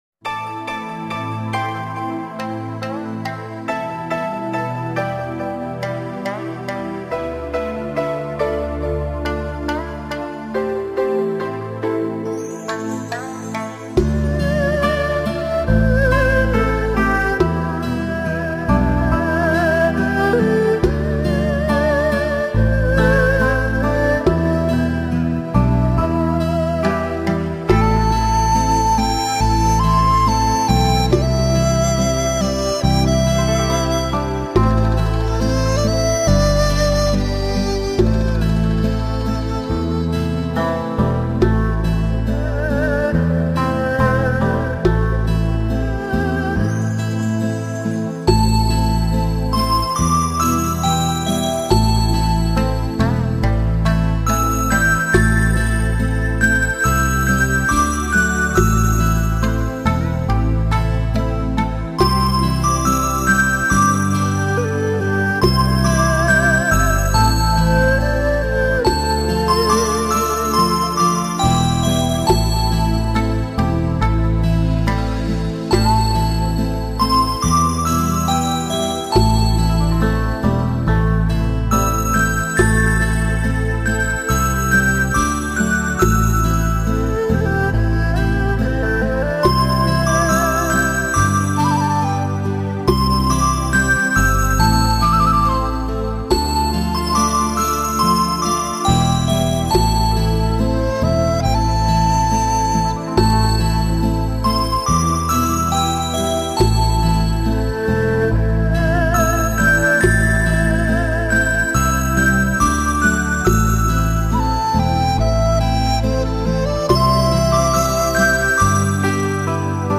» 07 Nhac Thien
Nhac Thien Dan Tranh (Meditation Music) 5 phut